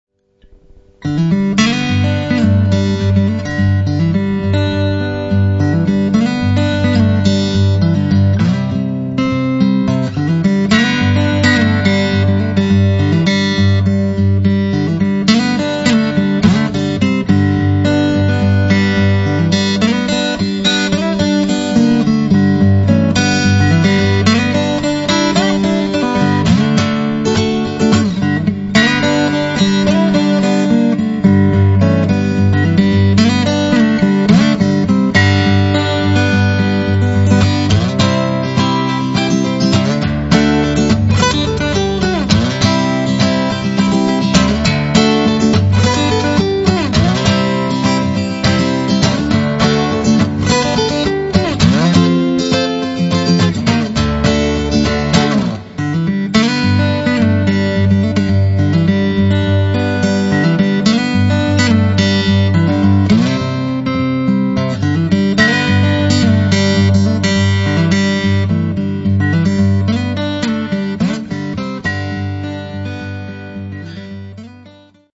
STYLE: Solo acoustic guitar.